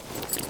tac_gear_34.ogg